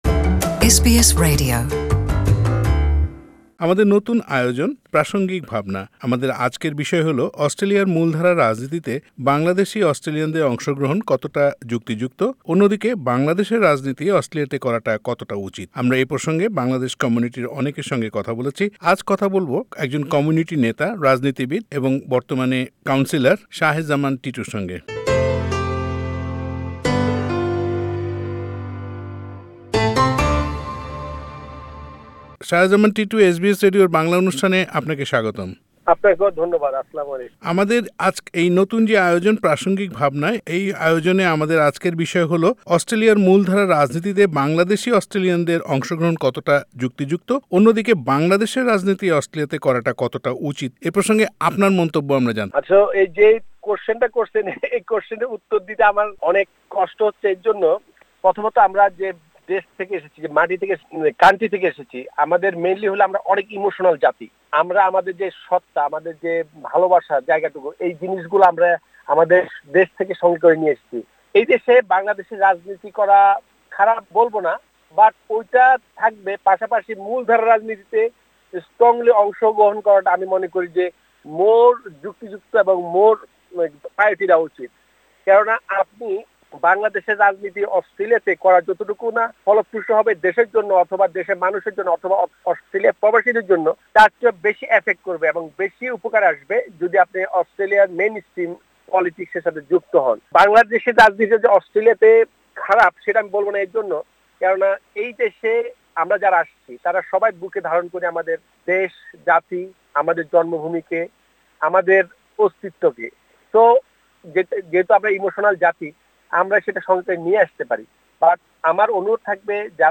‘প্রাসঙ্গিক ভাবনা’র আজকের বিষয়: অস্ট্রেলিয়ার মূলধারার রাজনীতিতে বাংলাদেশী অস্ট্রেলিয়ানদের অংশগ্রহণ কতোটা যুক্তিযুক্ত এবং বাংলাদেশের রাজনীতি অস্ট্রেলিয়ায় করার উপযোগিতা কতোটুকু? এসব নিয়ে এসবিএস বাংলার সঙ্গে কথা বলেছেন কেন্টারবেরি-ব্যাংকসটাউন কাউন্সিলের কাউন্সিলর মোহাম্মদ শাহে জামান টিটু।
কাউন্সিলর মোহাম্মদ শাহে জামান টিটুর সাক্ষাৎকারটি বাংলায় শুনতে উপরের অডিও প্লেয়ারটিতে ক্লিক করুন।